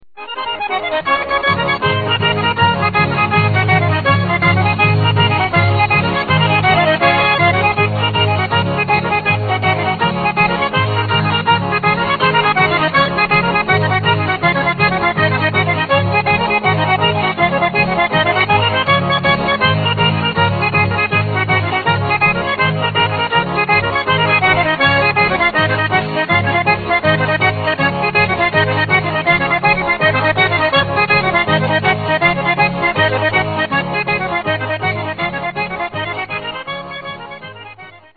Here is an excellent selection of field recordings for lovers of traditional dances and especially of accordion music.
While in the south of Abruzzo the saltarella is often danced in small circles, around Teramo it is danced by couples to a relatively fast tempo.
Saltarella